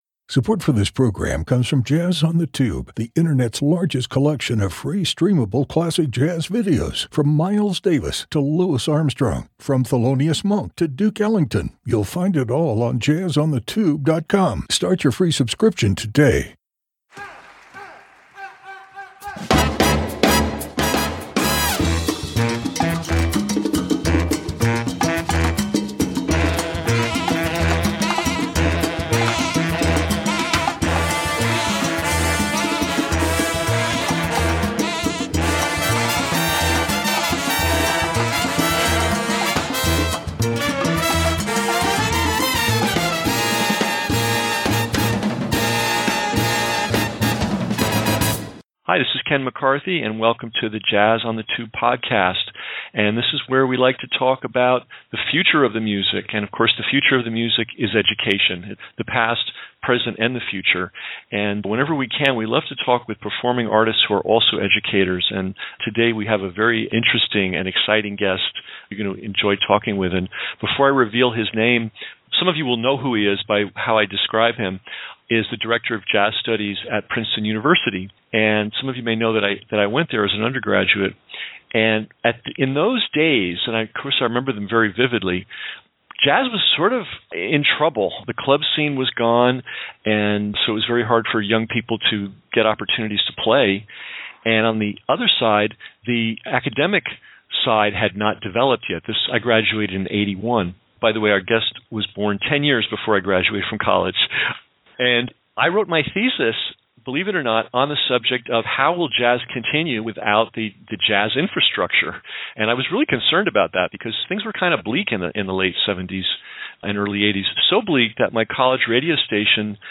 Interview with Rudresh Mahanthappa
Artist-Educators, Blog, Jazz on the Tube Interview, Podcasts